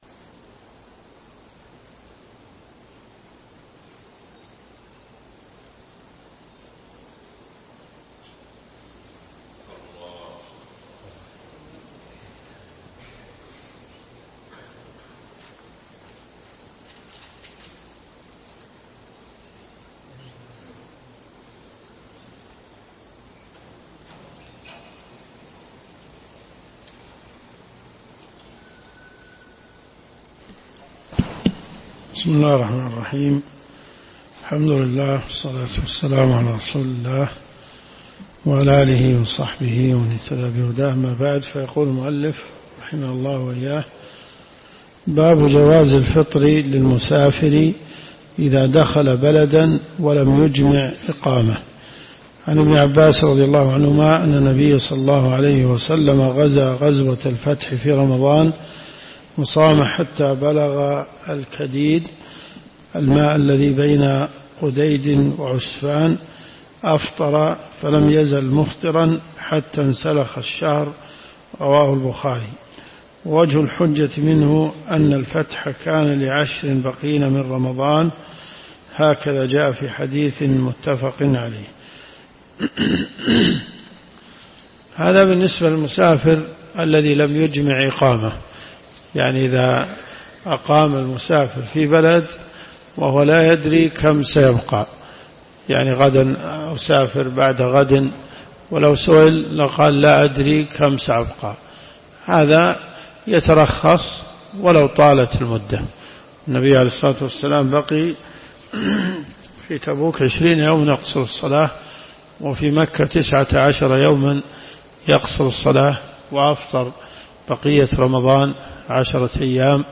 دروس صوتيه ومرئية تقام في جامع الحمدان بالرياض